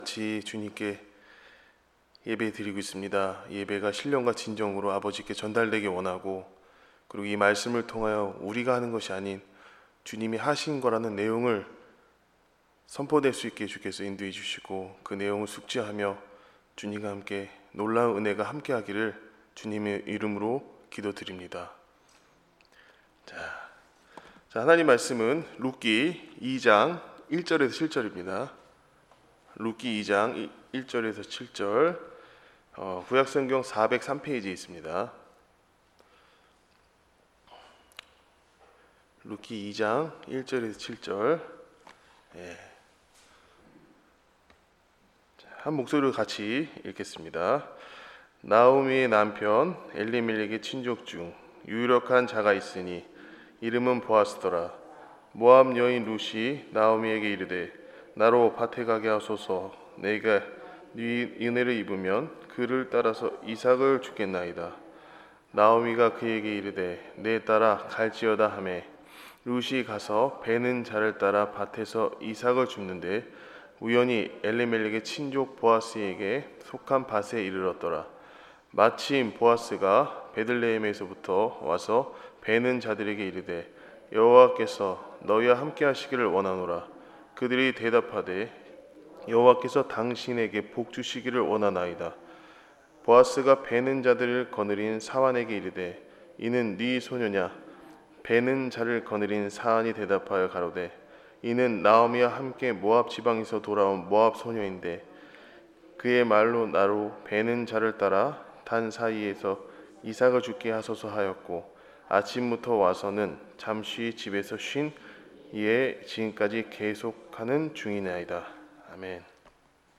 수요예배